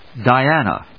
音節Di・an・a 発音記号・読み方
/dɑɪˈænə(米国英語)/